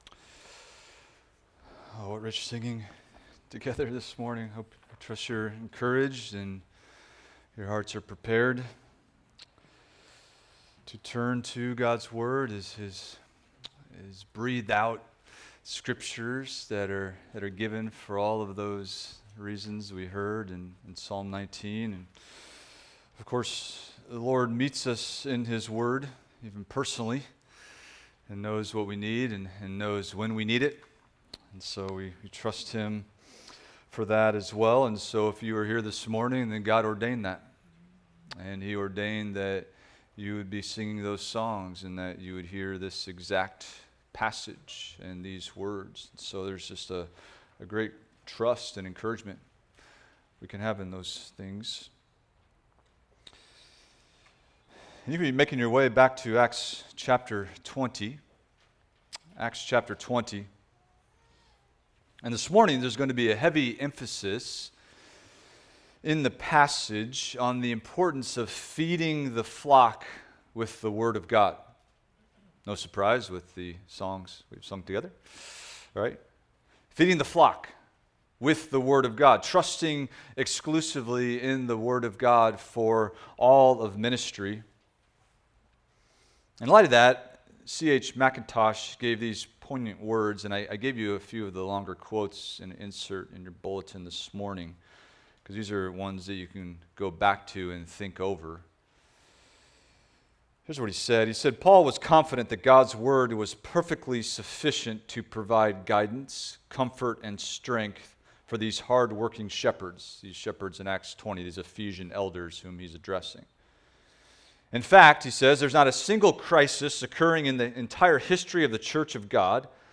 Expository Preaching about the Birth of Christ